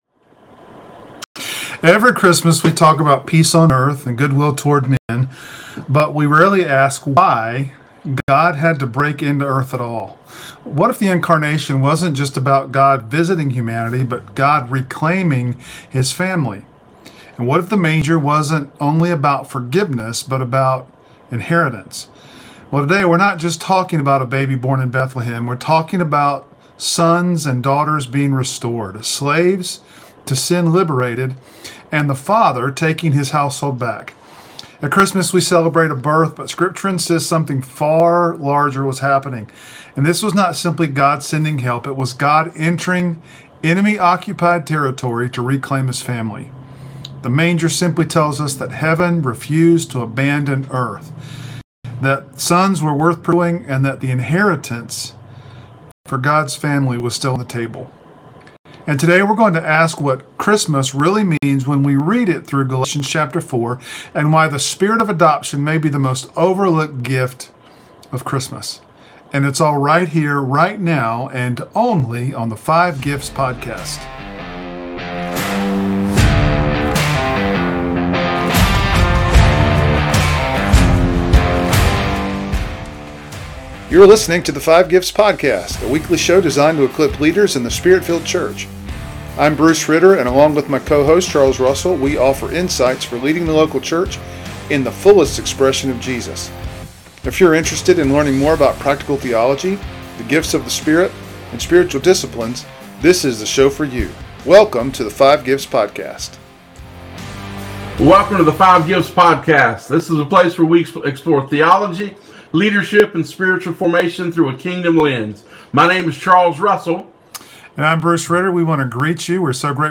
Drawing from the Divine Council worldview, they show how Jesus enters a fractured cosmic order as the faithful Son, and how the Spirit of adoption restores believers to true sonship. Through a dynamic Q&A format, this episode connects deep theology with practical application—reshaping how we think about evangelism, discipleship, and prayer.